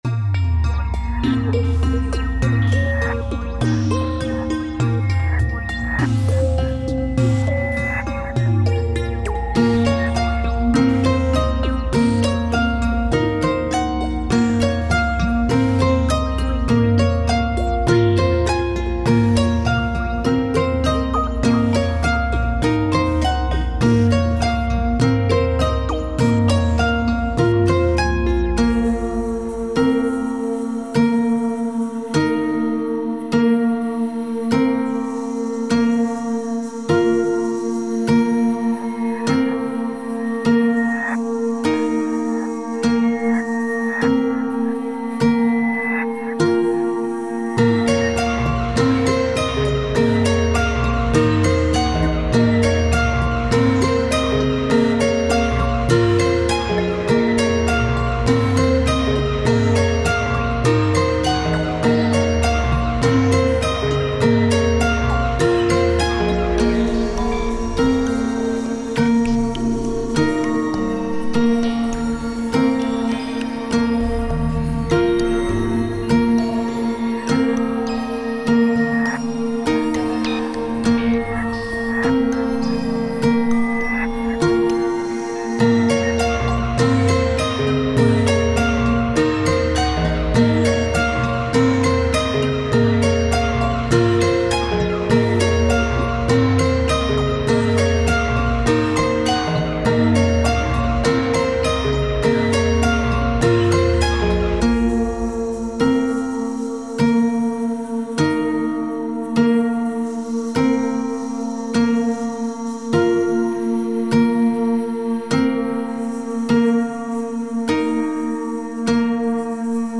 ミステリアスな雰囲気をベースに、逆再生や人の声を使ったシンセなどで不気味さを加えている。